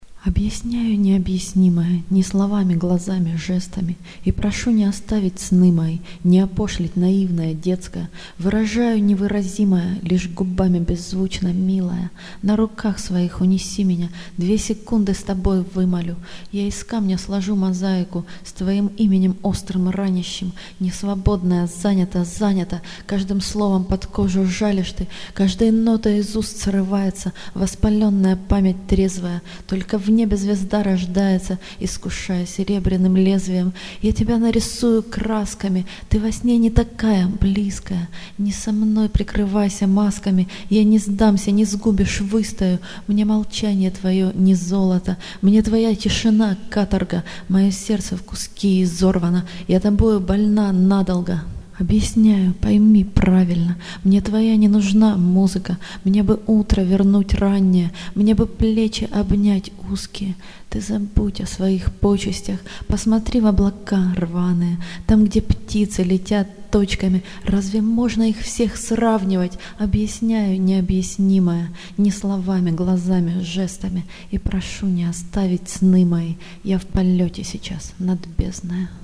Декламация (2610)